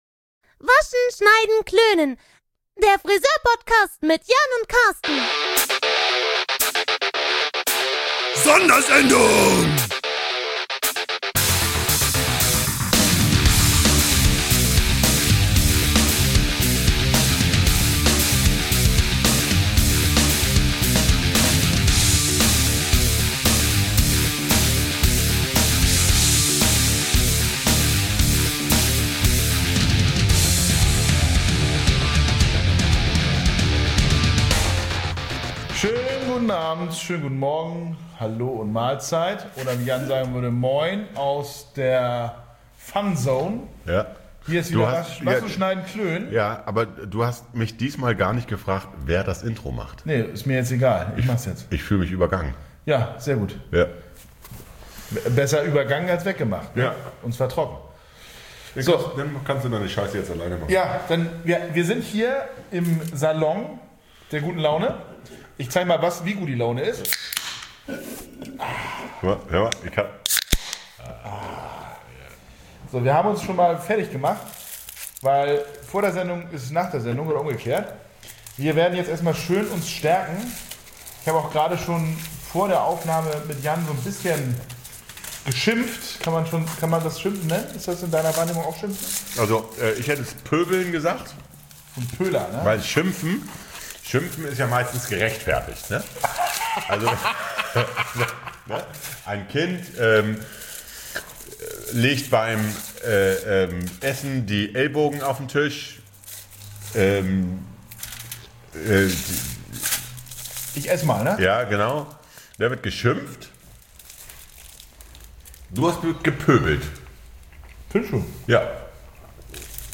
Es wird gemeinsam gegessen, gelacht, diskutiert
Selbst Telefon-Joker kommen zum Einsatz!